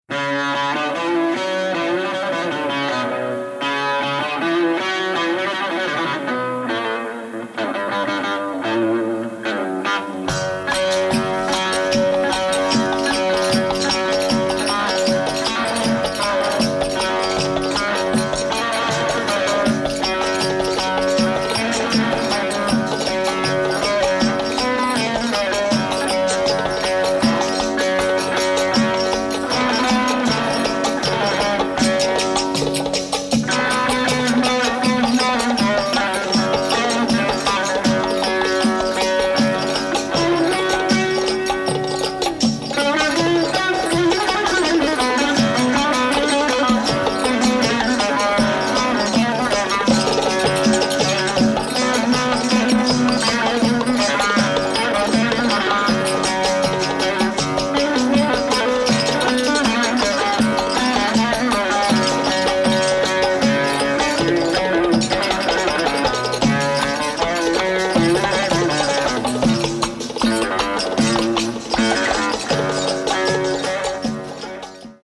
1970年代から80年代にかけて録音されたトルコ音楽の中からエレクトリック・サズに焦点をあてた音源集！